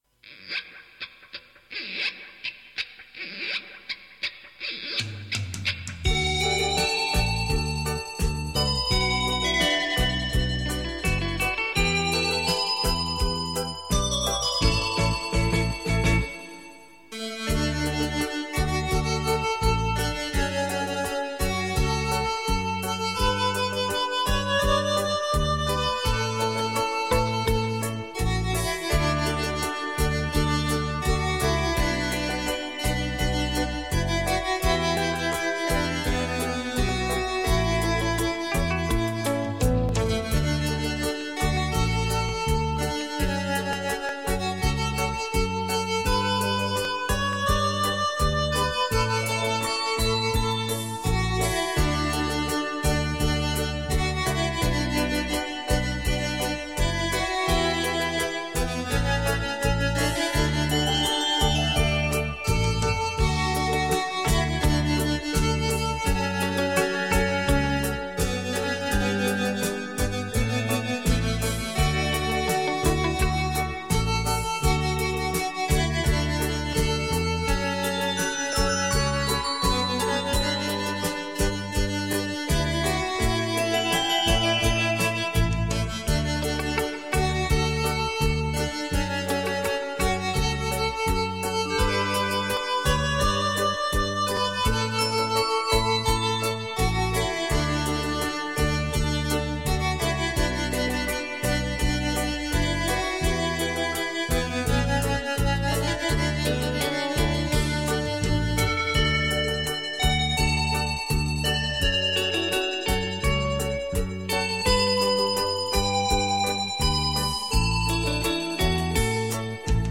轻快、跳耀、如首首小诗中吟诵，如条条小溪在流淌，如阵阵清风在抚慰，这如歌的行板呀！